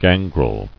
[gan·grel]